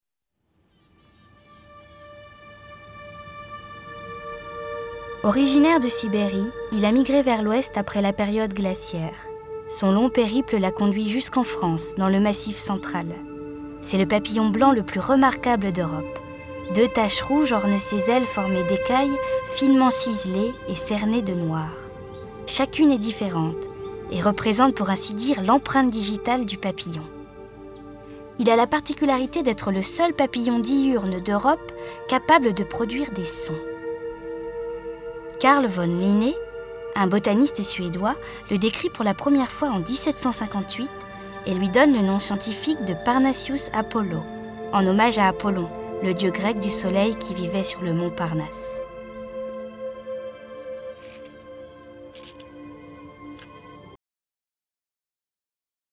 Voix off documentaire
8 - 52 ans - Soprano